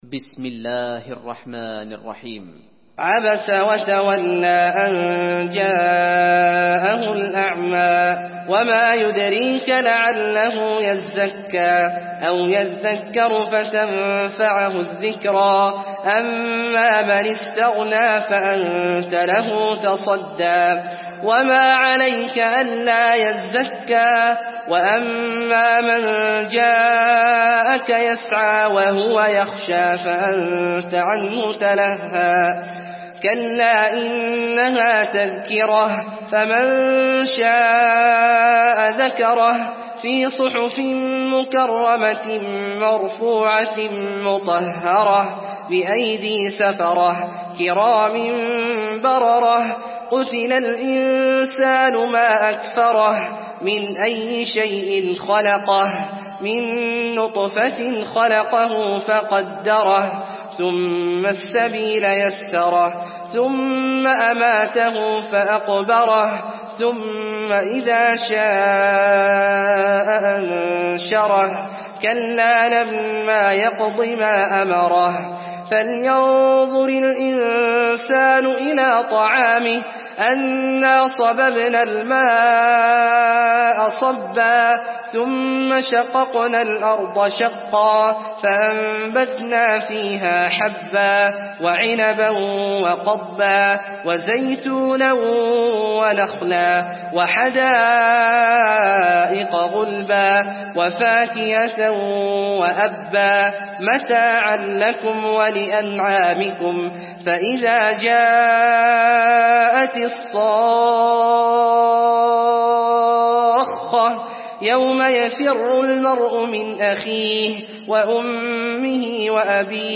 المكان: المسجد النبوي عبس